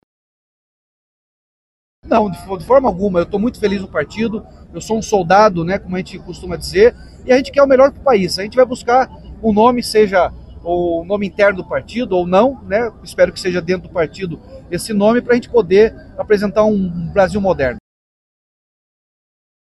Em agenda pública mais cedo nesta semana, antes da divulgação da pesquisa, o governador do estado comentou o cenário eleitoral já de olho em 2026 e falou em prol de uma candidatura própria do PSD, tanto nacionalmente quanto no Paraná.